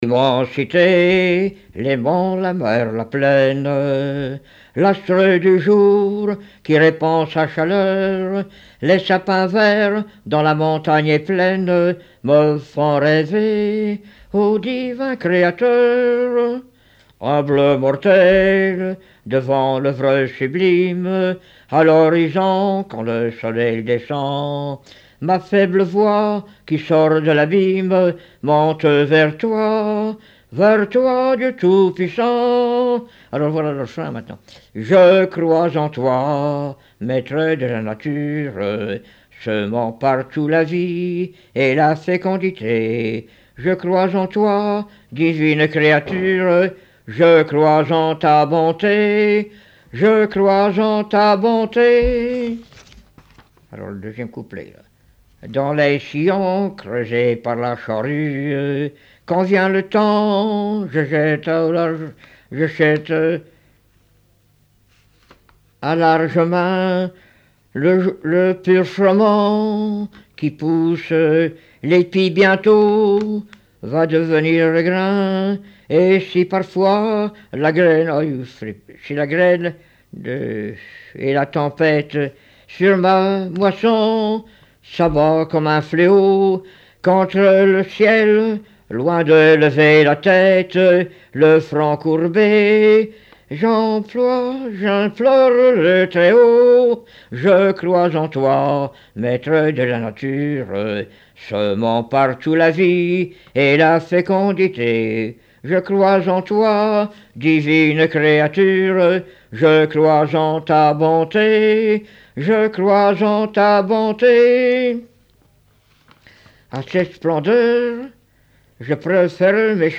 Chansons du début XXe siècle
Pièce musicale inédite